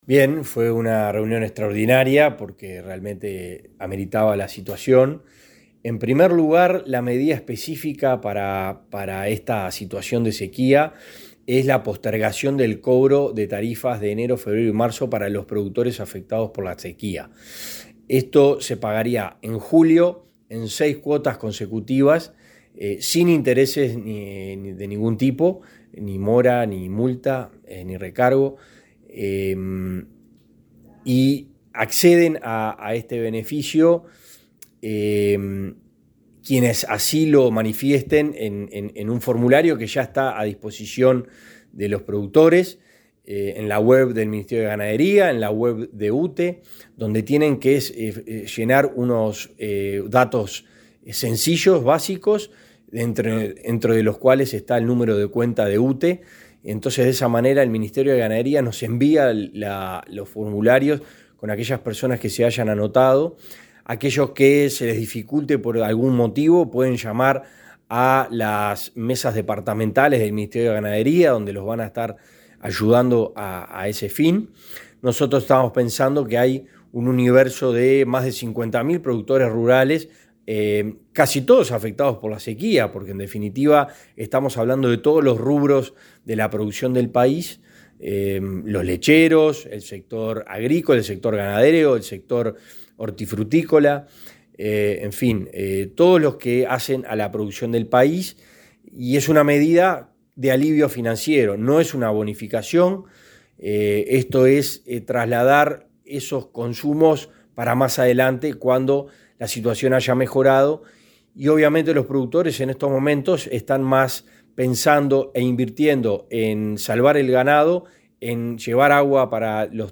Entrevista al director de UTE, Felipe Algorta